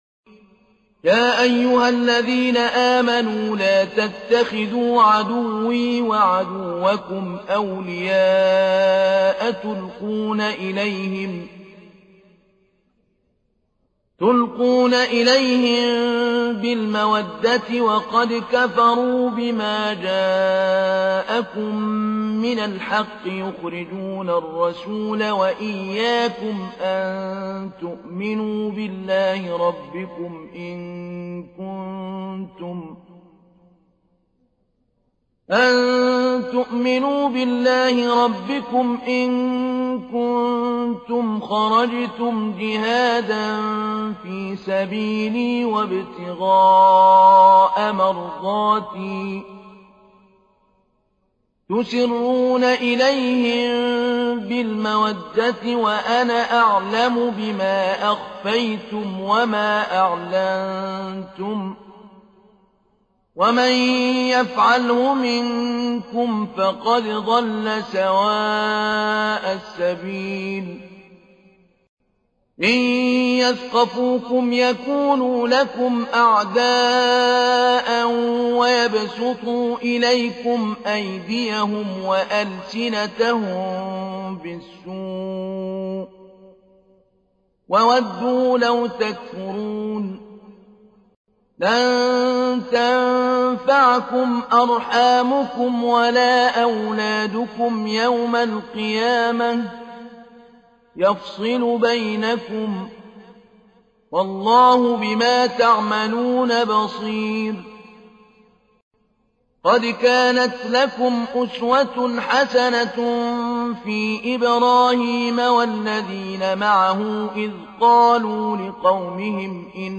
تحميل : 60. سورة الممتحنة / القارئ محمود علي البنا / القرآن الكريم / موقع يا حسين